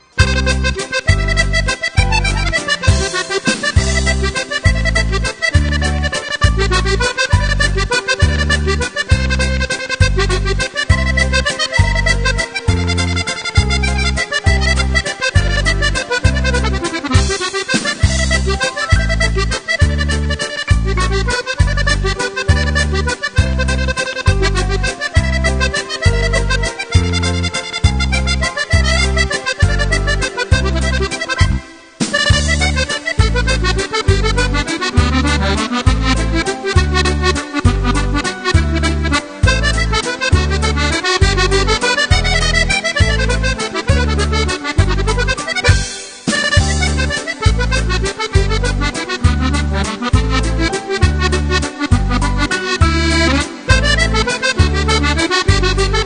Titres enchain?s pour danser